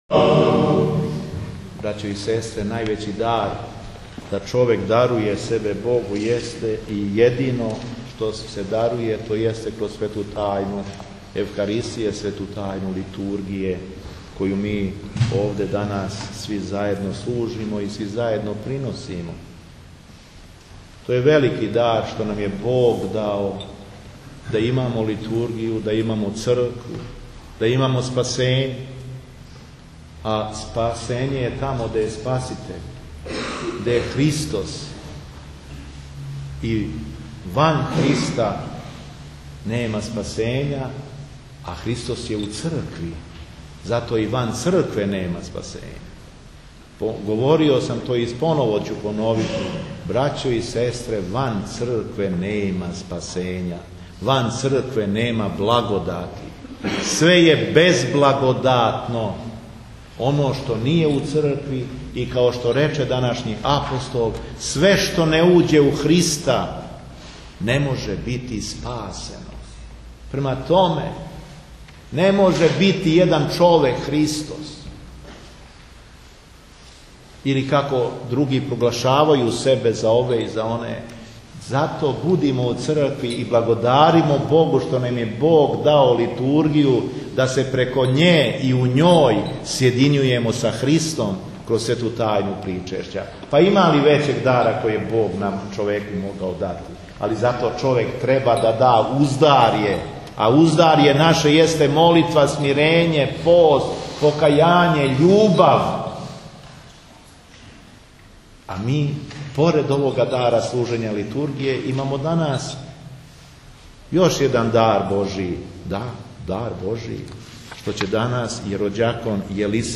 СВЕТА АРХИЈЕРЕЈСКА ЛИТУРГИЈА У МАТАРУШКОЈ БАЊИЊегово Преосвештенство Епископ шумадијски и администратор Епархије жичке, Господин Јован, служио је 08. марта 2013. године Свету Архијерејску литургију у храму Св. великомученика кнеза Лазара у Матарушкој Бањи уз саслужење осморице свештеника и тројице ђакона.
Беседа епископа шумадијског Г. Јована - 08. март 2013. године